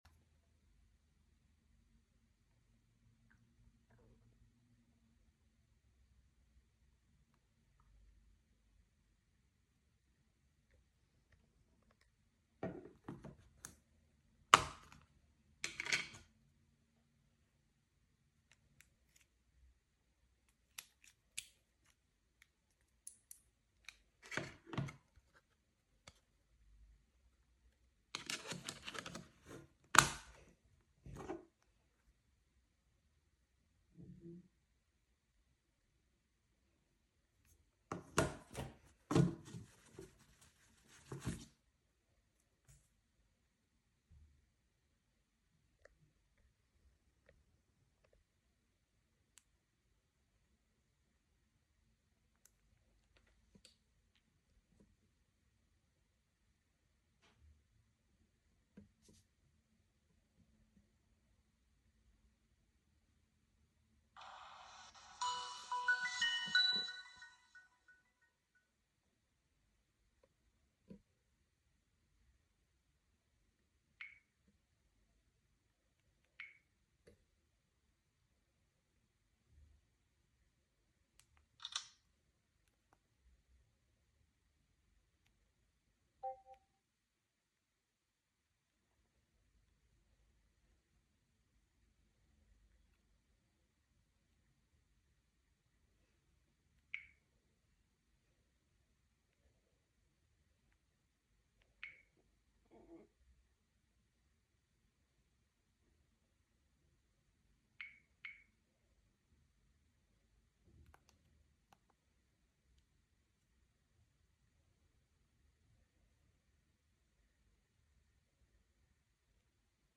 Here is a video showcasing the Samsung Star 3, it’s sounds, ringtones, wallpapers and a menu scroll of the phone.